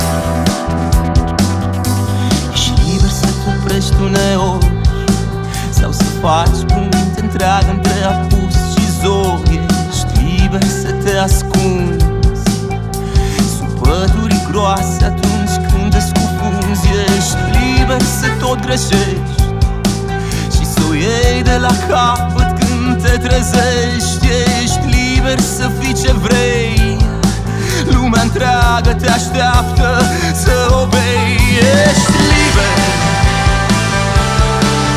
Жанр: Альтернатива
# New Wave